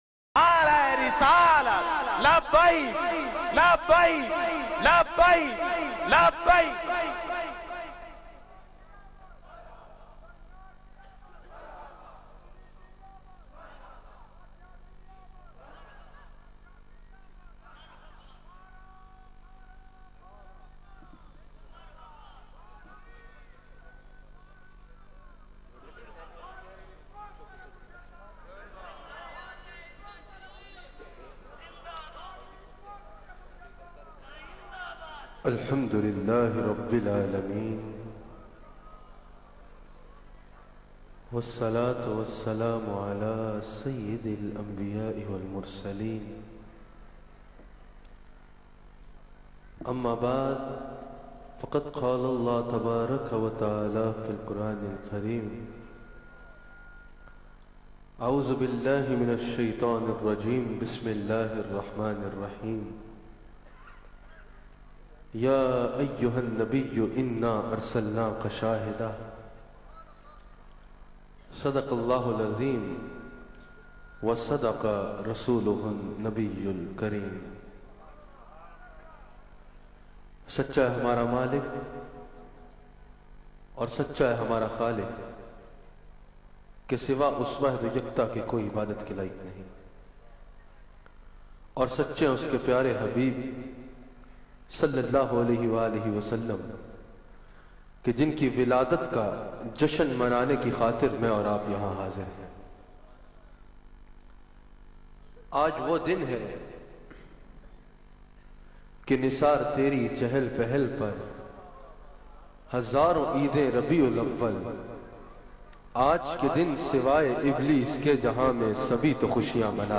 12 Rabi Ul Awal bayan mp3